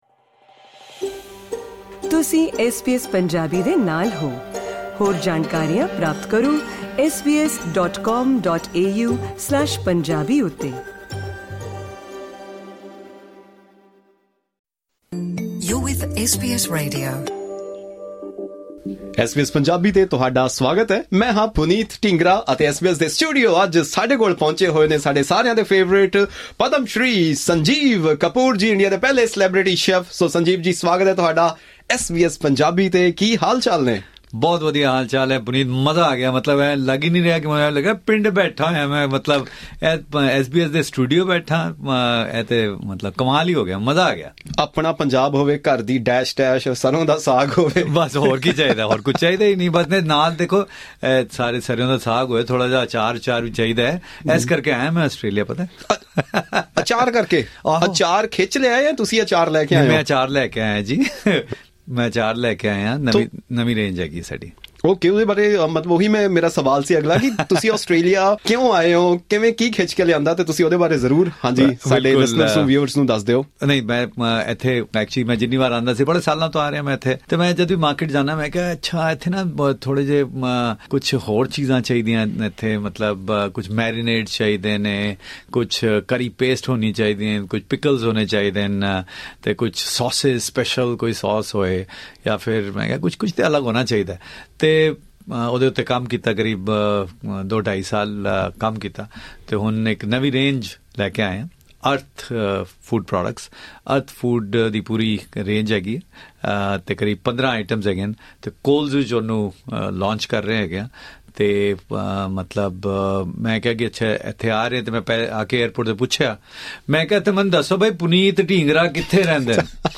In a conversation with SBS Punjabi, he explained why 'khichdi' is his most special dish. Additionally, Sanjeev Kapoor shared some tips for Punjabi restaurant owners in Australia. He also discussed cooking, preserving and promoting culture through traditional food, and much more in this podcast.
India's first celebrity chef Sanjeev Kapoor visits SBS Punjabi studio.